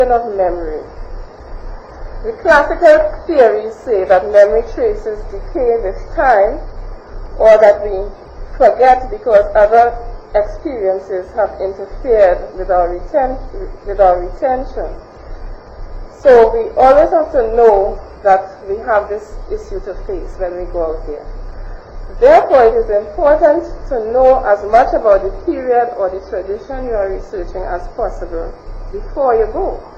1 audio cassette